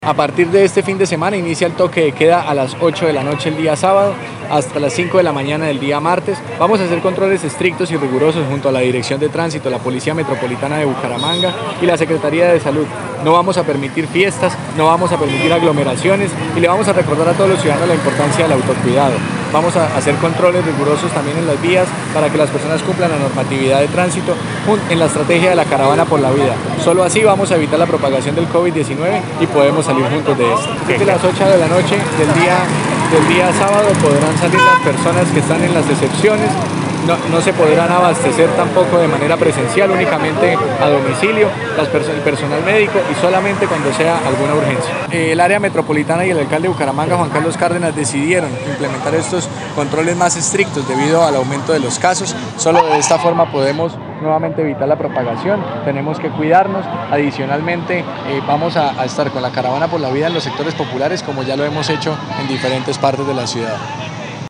Audio: José David Cavanzo, secretario del Interior de Bucaramanga